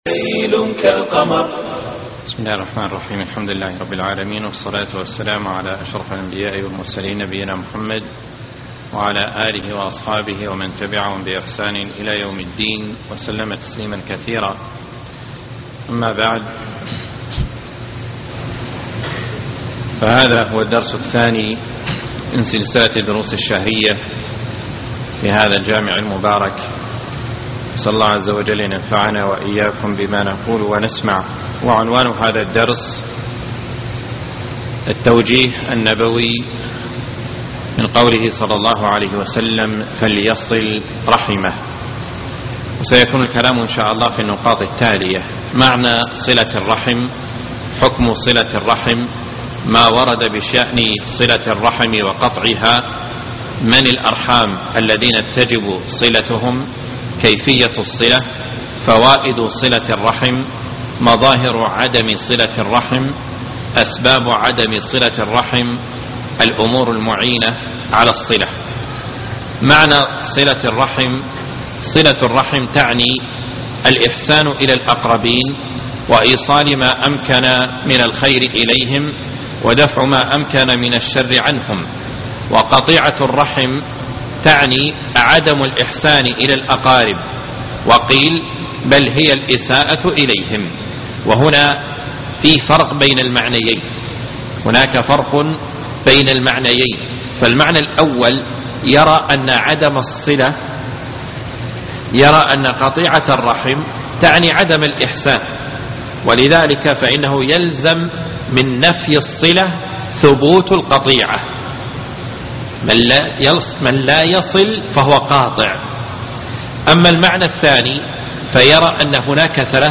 محاضرة اليوم